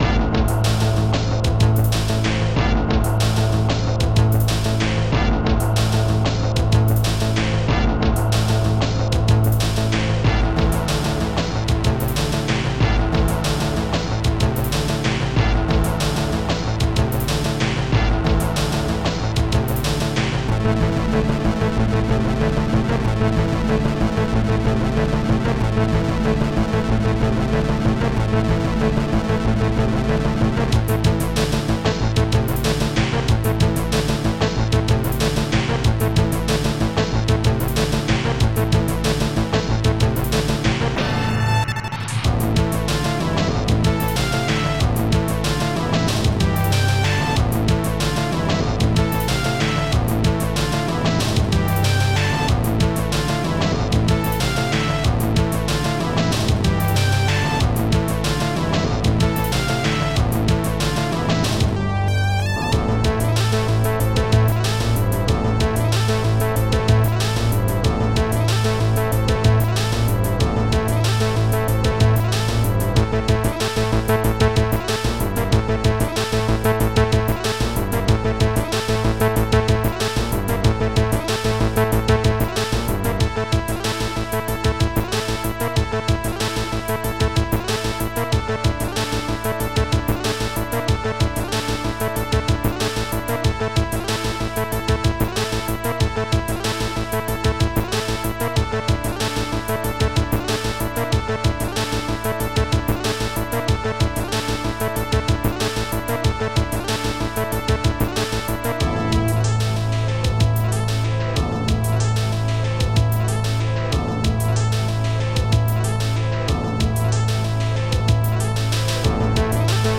Protracker and family
ST-00:hihat2
St-00:u.tom-bass2